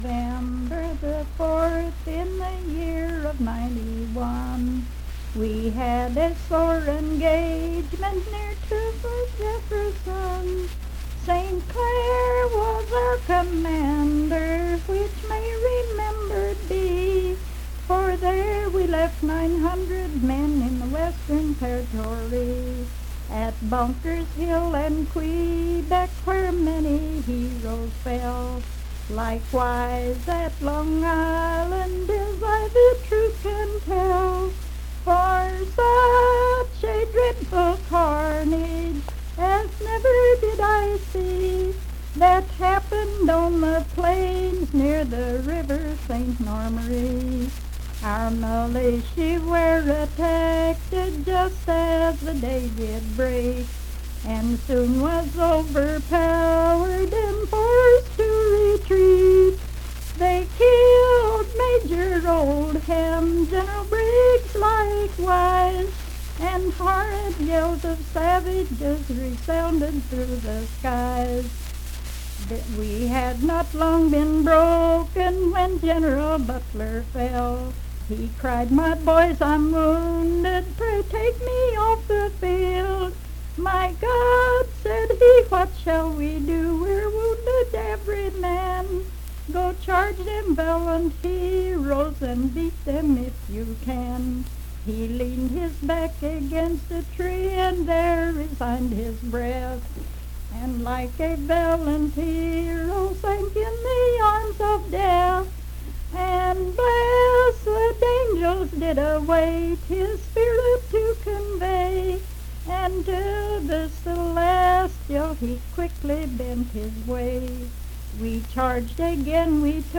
Unaccompanied vocal music
Verse-refrain. 9d(4).
Voice (sung)
Moorefield (W. Va.), Hardy County (W. Va.)